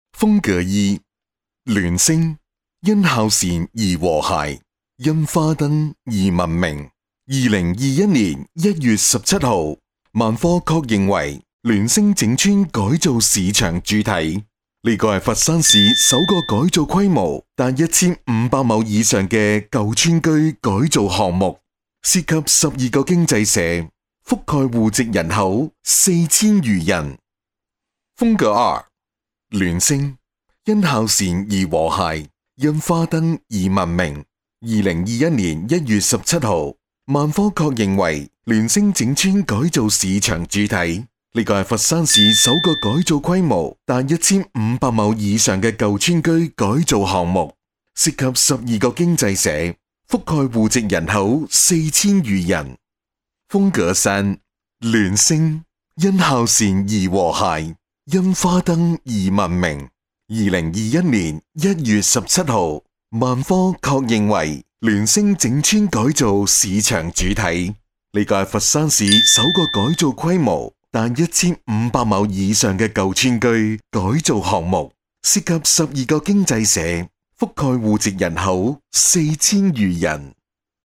万科联星 - 粤语男1号 - 光影嘉乐旗舰店